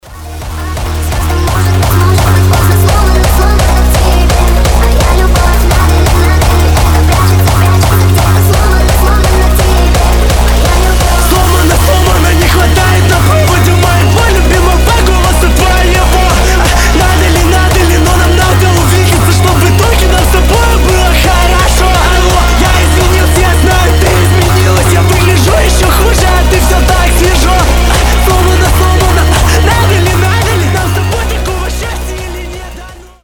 Громкие рингтоны
Рингтоны со словами , Рингтоны техно , Фонк
Дуэт